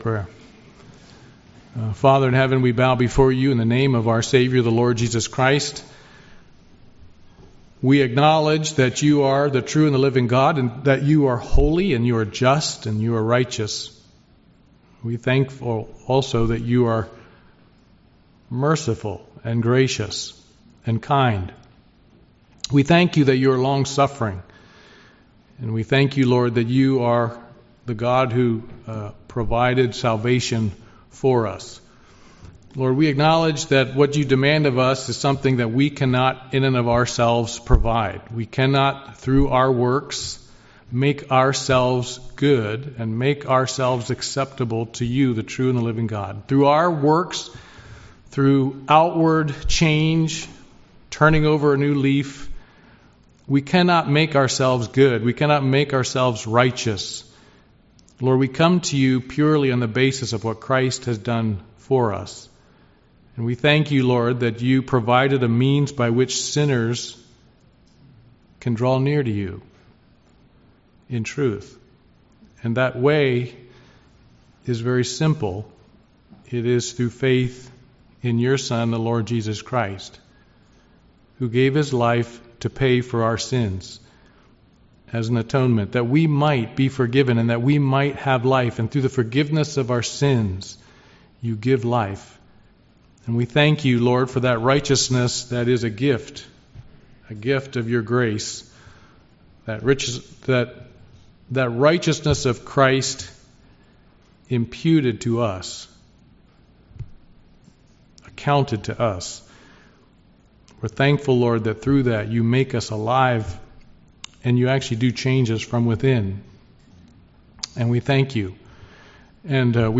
Passage: Hebrews 12 Service Type: Sunday Morning Worship